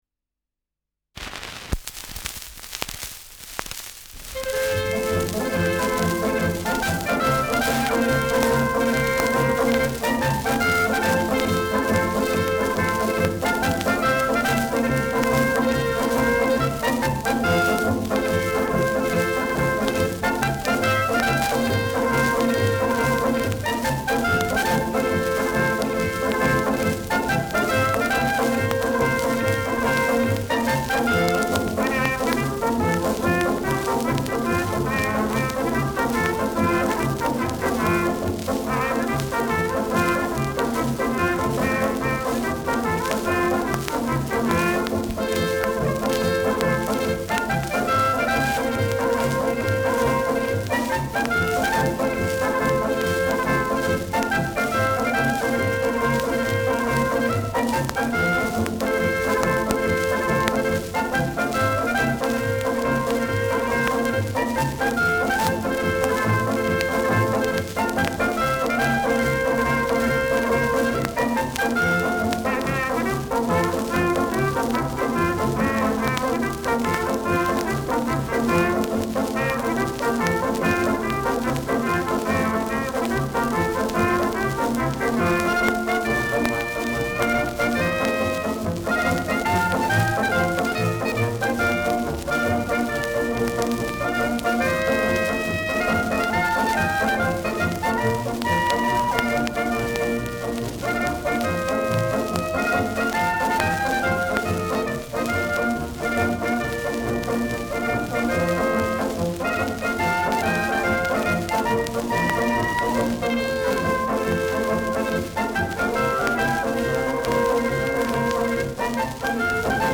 Schellackplatte
[Berlin] (Aufnahmeort)